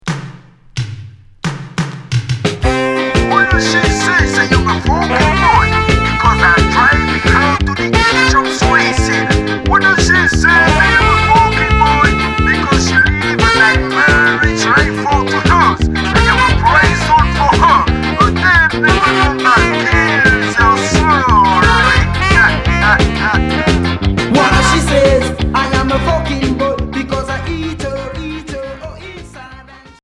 Reggae rub-a-dub